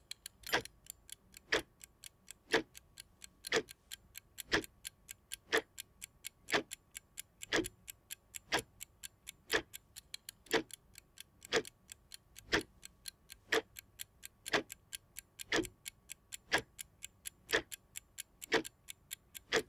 tictac.mp3